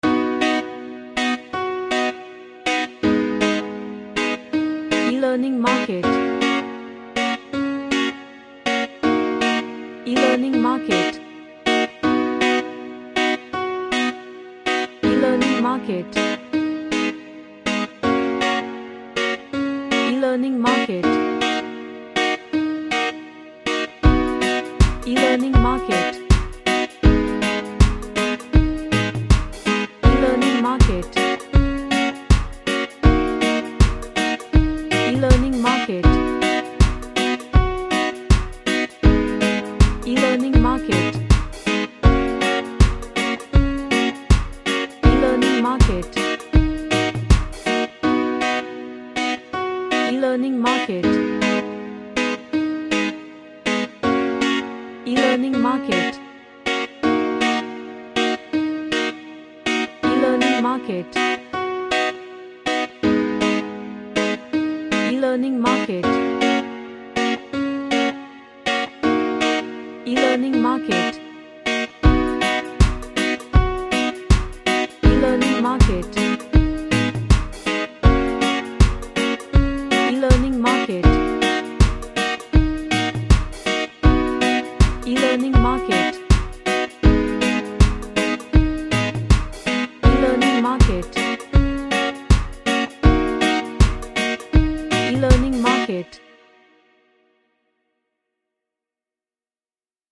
A reggae happy track.
Happy